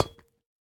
Minecraft Version Minecraft Version snapshot Latest Release | Latest Snapshot snapshot / assets / minecraft / sounds / block / copper / step6.ogg Compare With Compare With Latest Release | Latest Snapshot